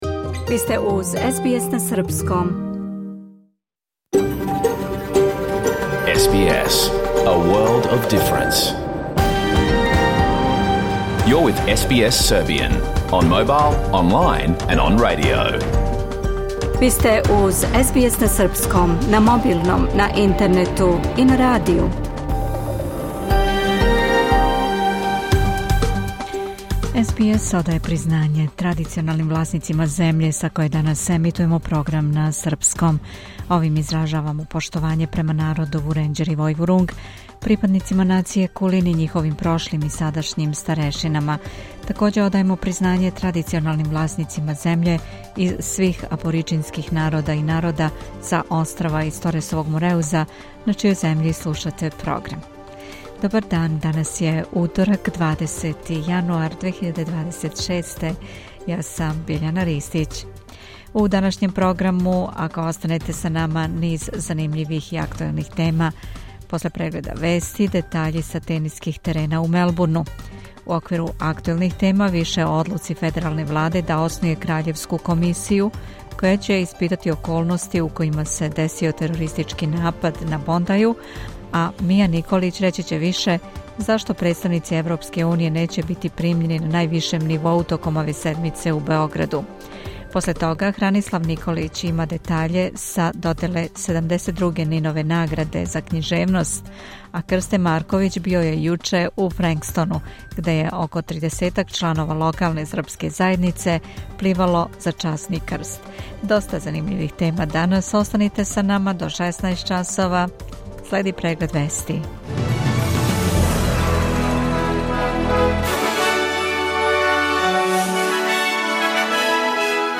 Програм емитован уживо 20. јануара 2026. године